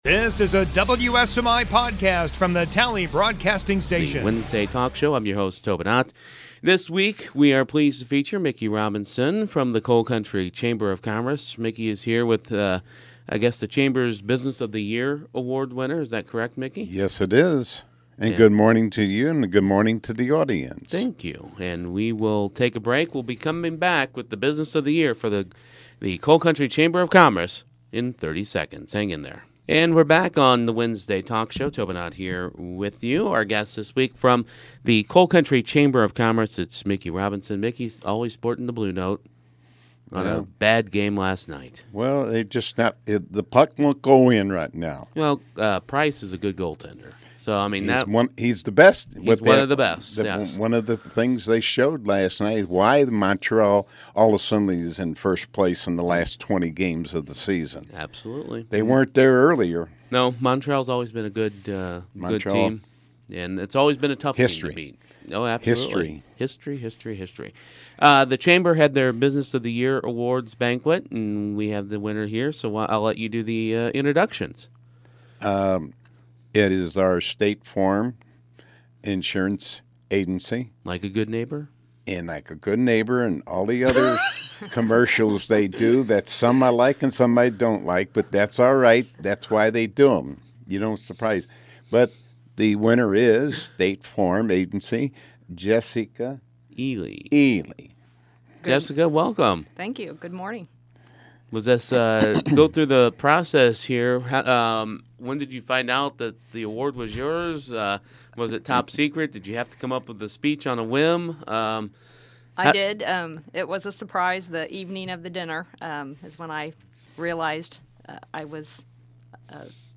Podcasts - Wednesday Talk